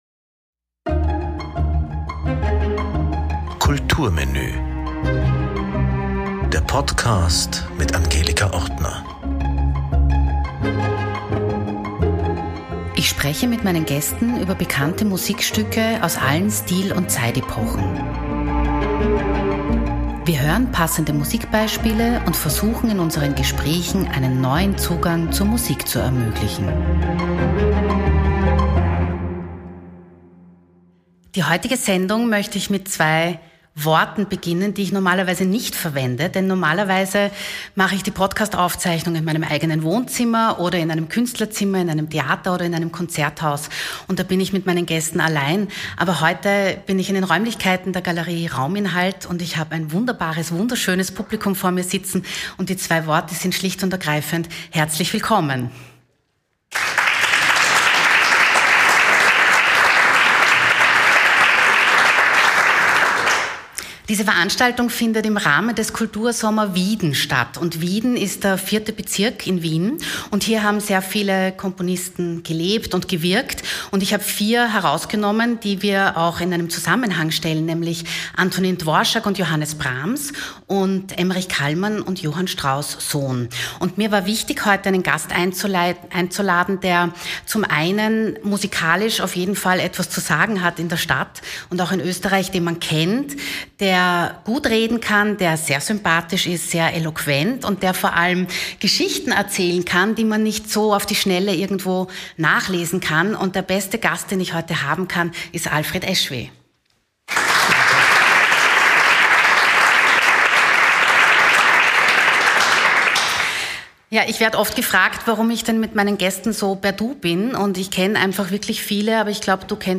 Diese Folge wurde live vor Publikum aufgenommen. Inhaltlich geht es um Komponisten, die im 4. Bezirk in Wien gewohnt und gewirkt haben: Johannes Brahms, Antonín Dvořák, Emmerich Kálmán und Johann Strauss Sohn.
Darüber hinaus sprechen wir über Verleger und Interpreten der damaligen Zeit, die eng mit den Komponisten zusammen arbeiteten. Man kommt von einem ins andere – eine sehr informative Folge mit freudiger Musik!